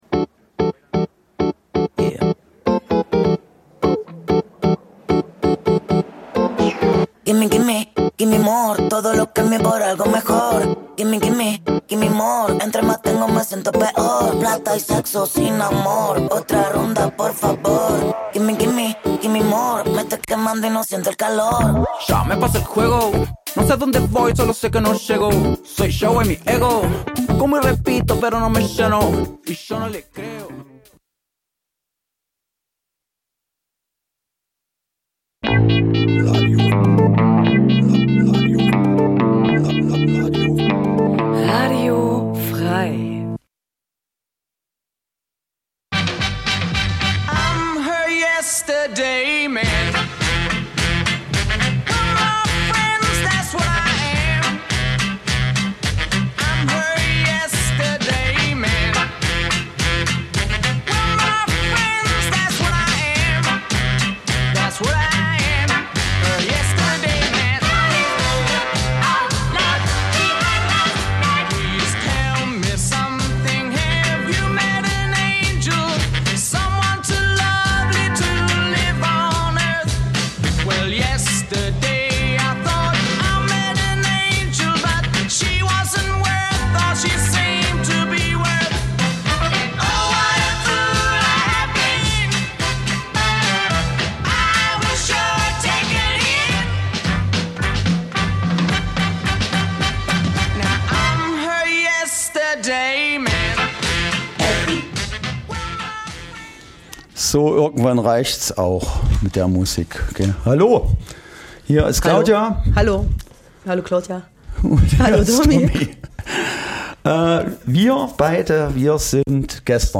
Amuse gueuls der Historie an harmonisierender Musikgarnitur, abgerundet durch einen Aperitiv mit differierendem Gehalt der Marke Blaba.